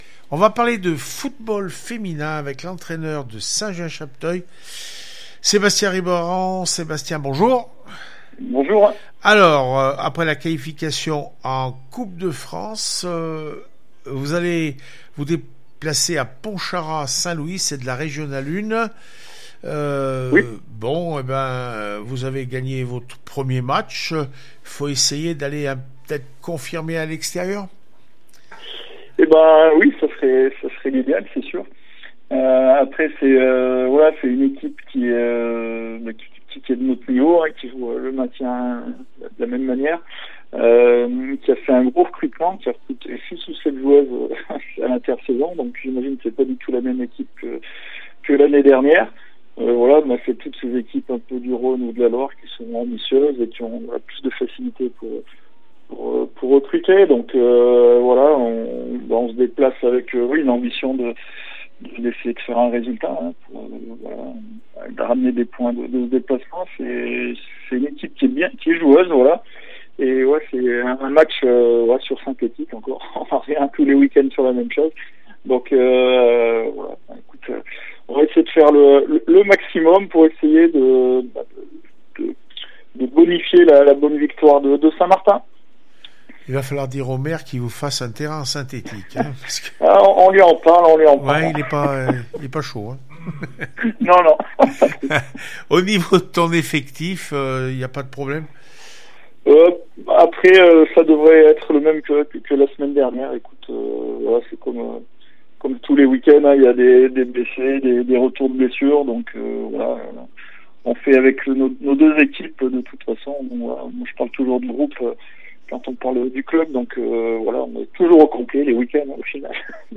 10 octobre 2020   1 - Sport, 1 - Vos interviews, 2 - Infos en Bref   No comments